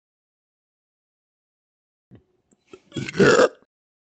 ERUCTO SENSUAL
eructo-sensual.mp3